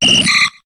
Cri de Poissirène dans Pokémon HOME.